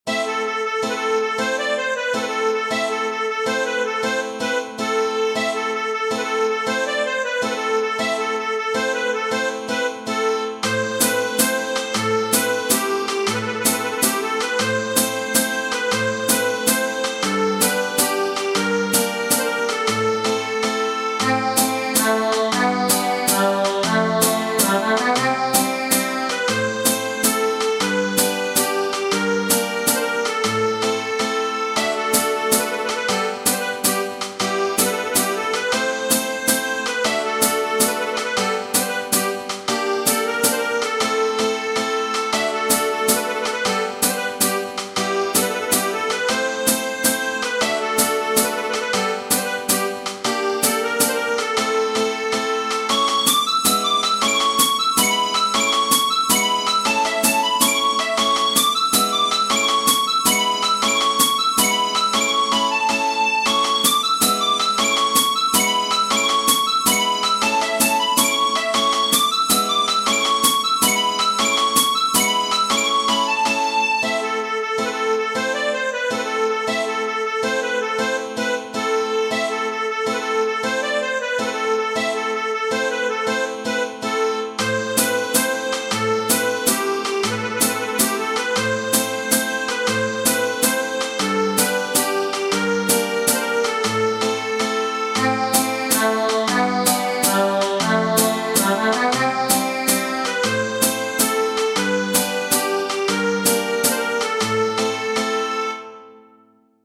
Tradizionale Genere: Folk La racenica è una danza e una musica tradizionale originaria della Bulgaria, spesso considerata danza nazionale.